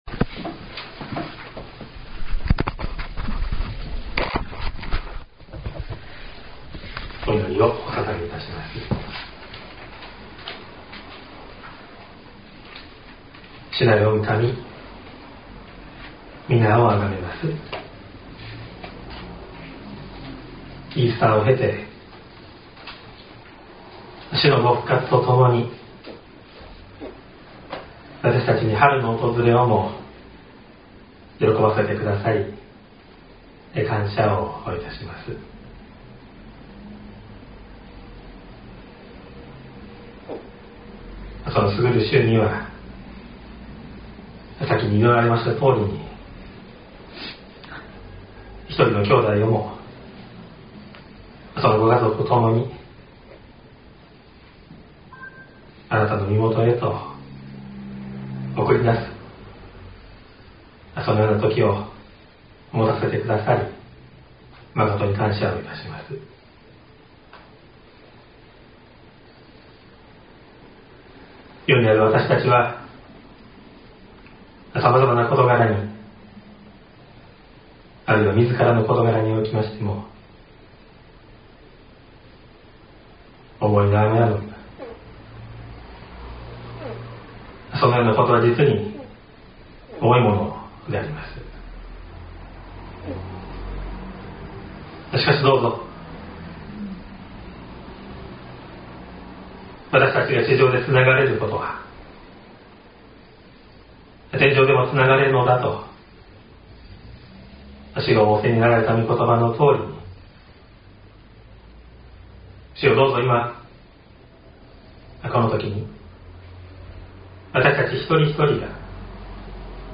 音声ファイル 礼拝説教を録音した音声ファイルを公開しています。